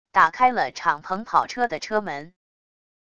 打开了敞篷跑车的车门wav音频